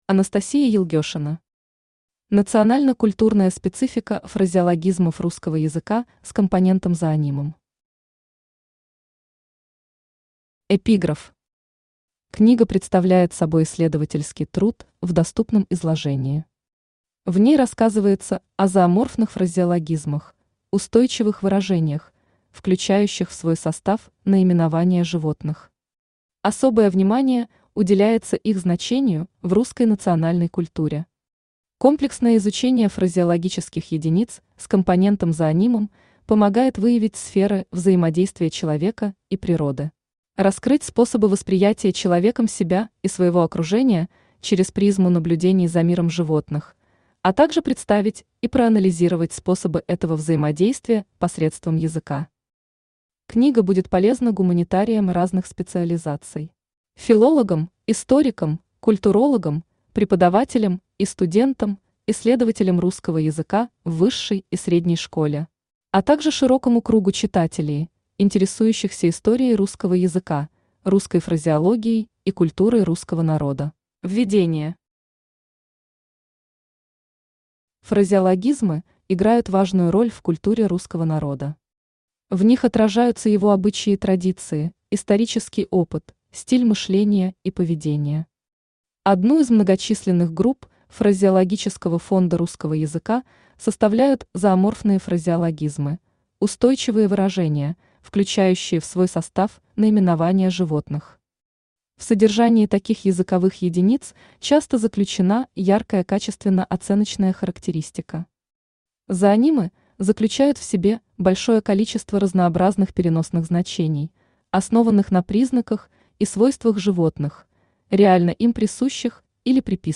Аудиокнига Национально-культурная специфика фразеологизмов русского языка с компонентом-зоонимом | Библиотека аудиокниг